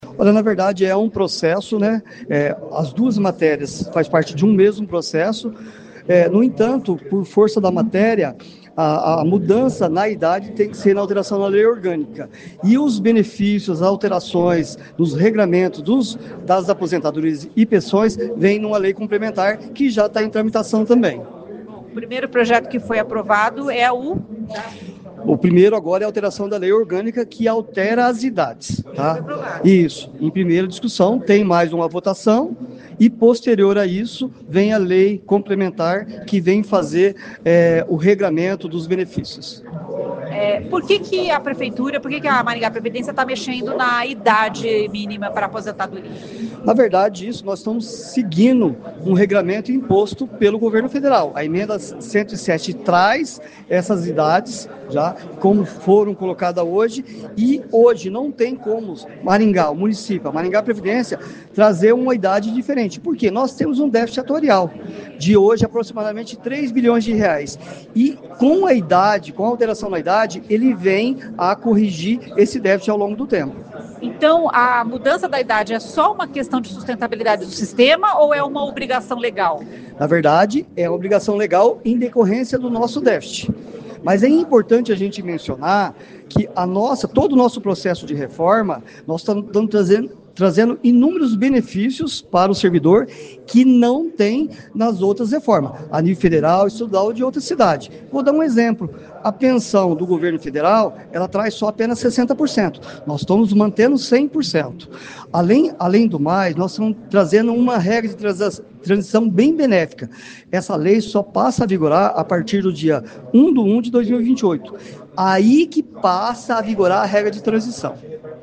O presidente da Maringá Previdência, Edson Paliari, diz que o objetivo é dar sustentabilidade financeira aos sistema previdenciário municipal.